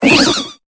Cri de Babimanta dans Pokémon Épée et Bouclier.